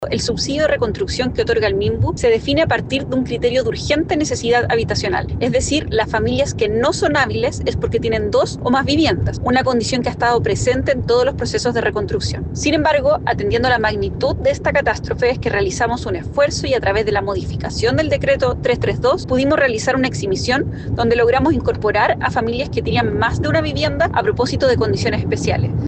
En relación al 21,1% de las familias afectadas que no son consideradas hábiles, la seremi de Vivienda y Urbanismo, Belén Paredes, declaró que tras el cambio del decreto 332, es que se logró incorporar a familias que contaban con más de una vivienda al subsidio habitacional.